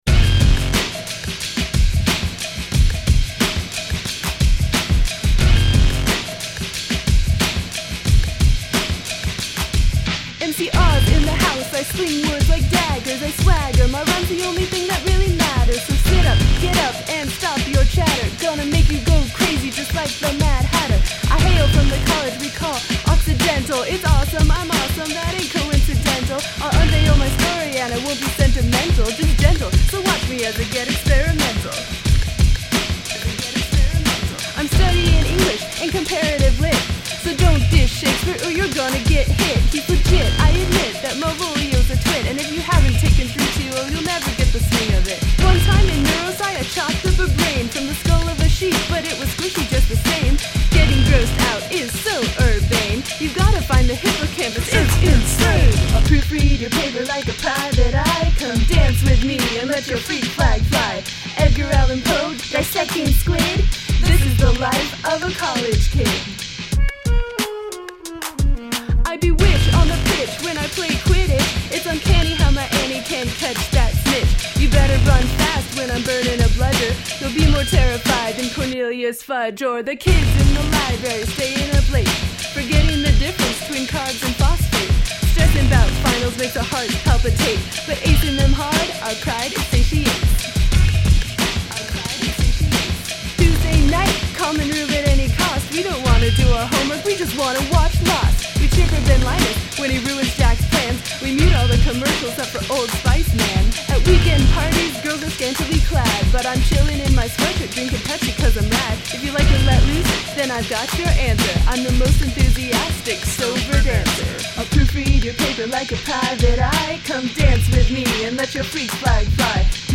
When I arrived at the homemade recording studio in a friend of the Bohemian's garage, I intended to do just that.
I enunciated.
After recording the vocals, I whipped out my alto sax and improvised a backing track.